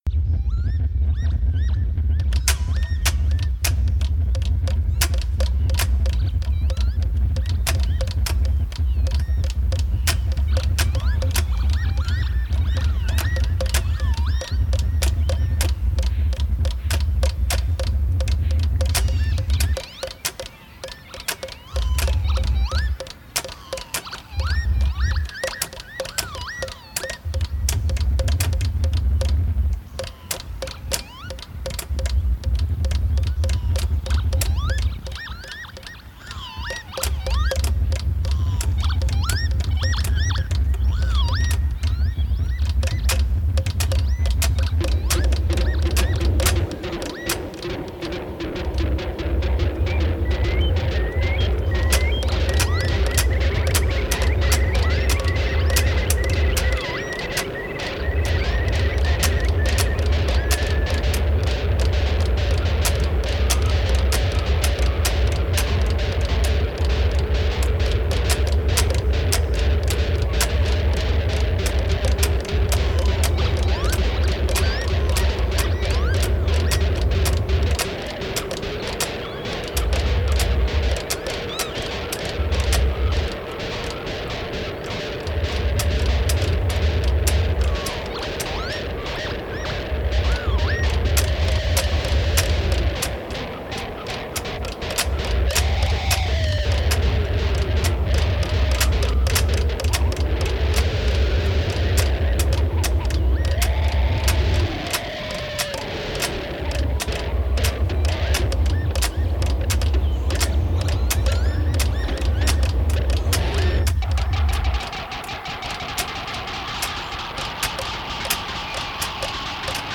(sax soprano, sax tenore);